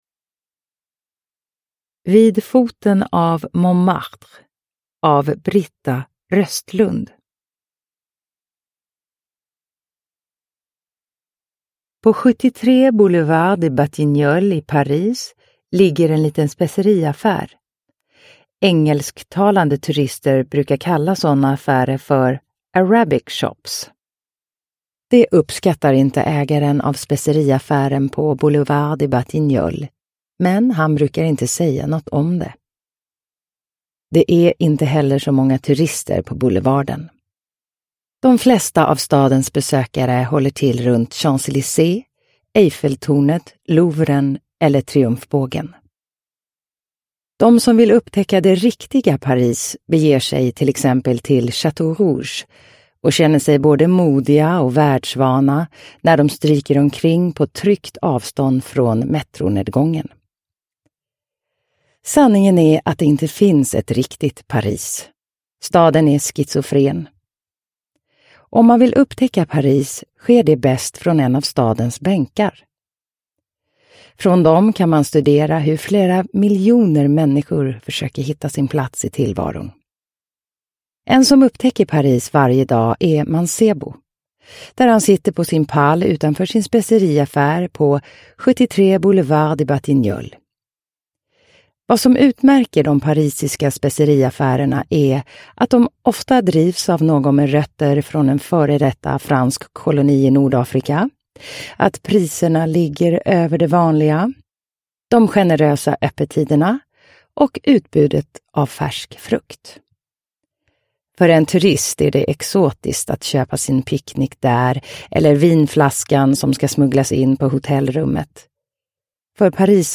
Vid foten av Montmartre – Ljudbok – Laddas ner